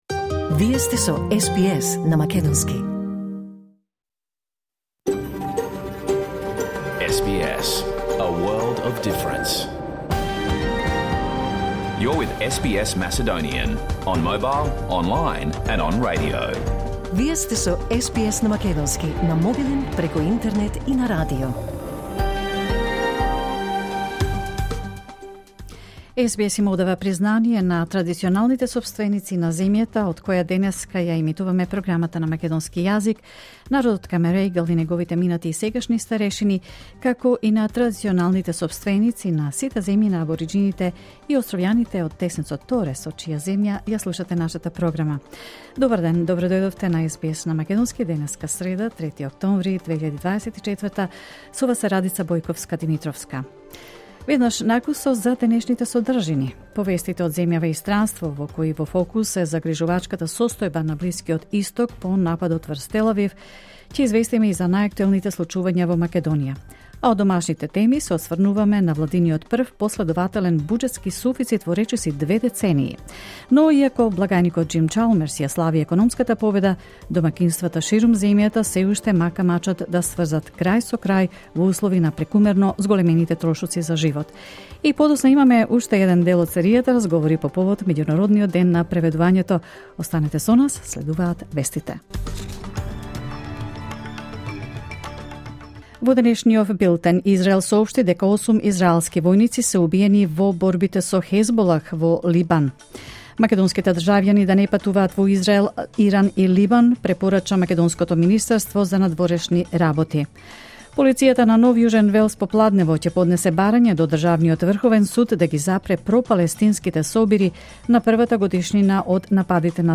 SBS Macedonian Program Live on Air 3 October 2024